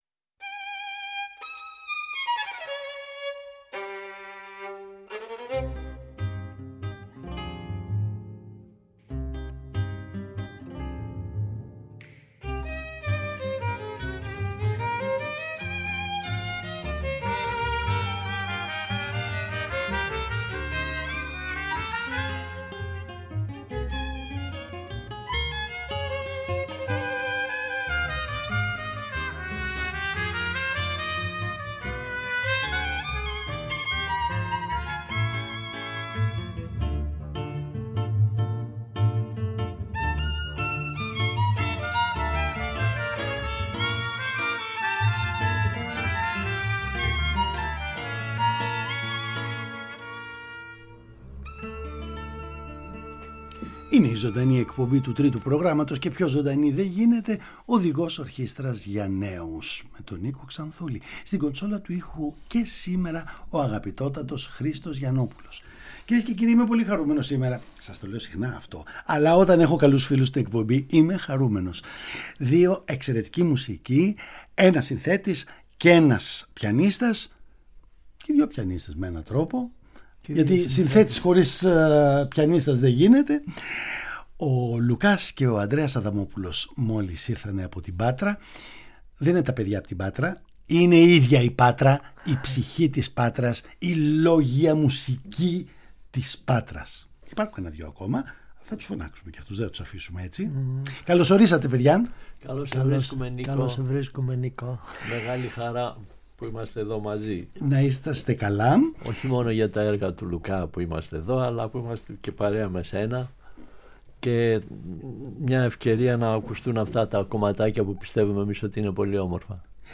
Παραγωγή-Παρουσίαση: Νίκος Ξανθούλης
Συνεντεύξεις